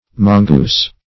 Mongoose \Mon"goose\, Mongoos \Mon"goos\, n.; pl. Mongooses